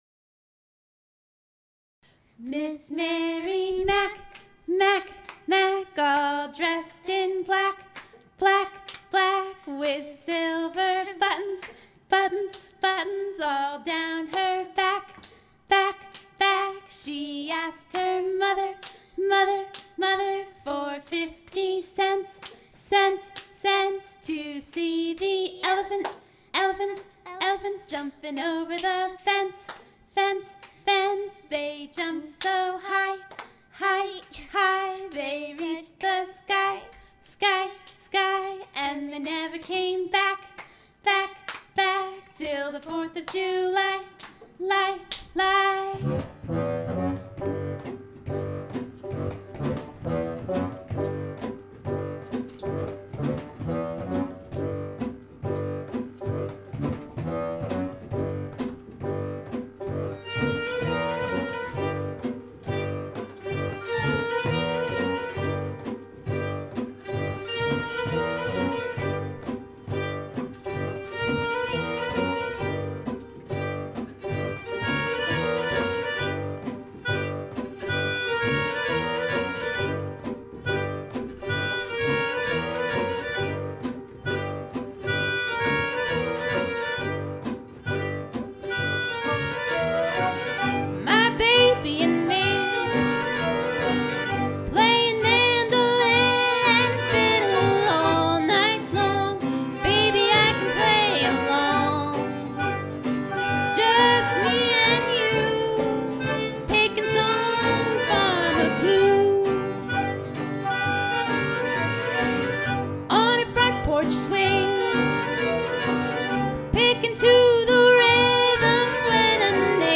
nyckelharpa